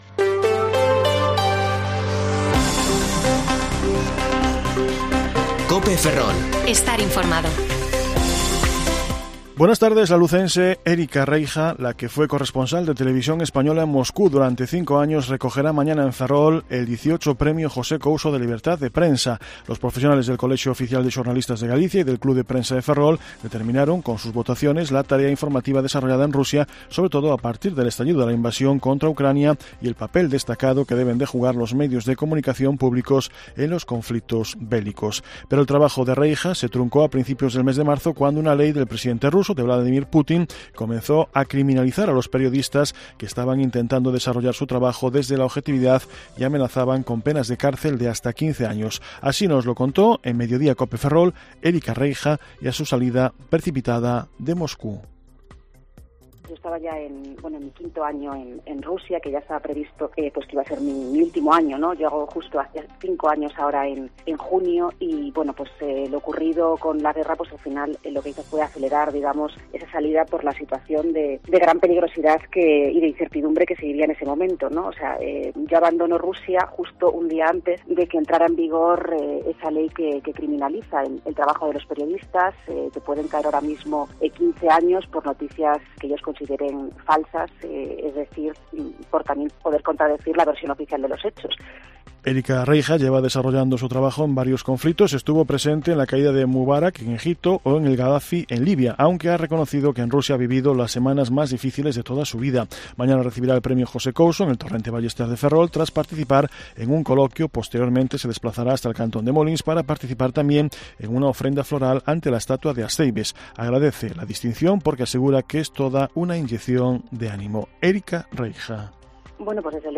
Informativo Mediodía COPE Ferrol 27/5/2022 (De 14,20 a 14,30 horas)